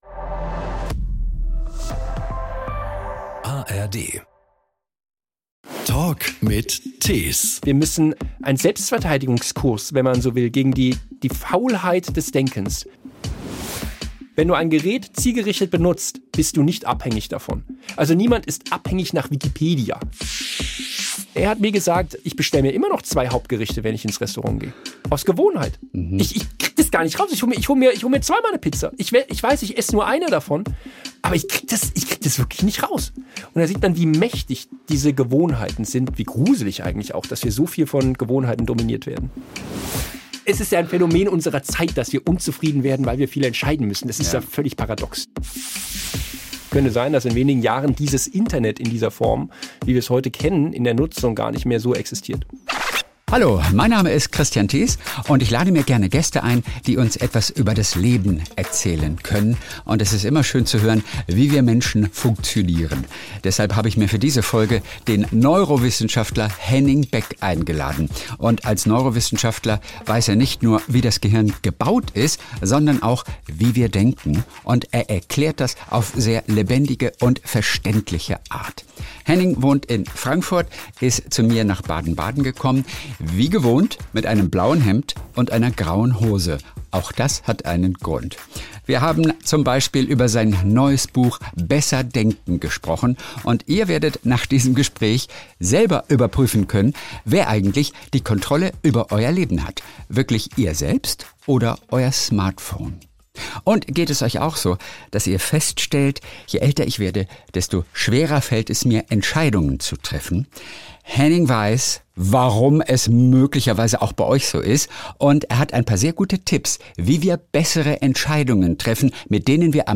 Jeden Sonntag unterhält er sich mit Stars, Promis und interessanten Menschen verschiedener Branchen. Kristian hat einfach Lust auf seine Gesprächspartner und spricht über die besonderen Geschichten der Popstars, Schauspieler, Autoren & Co..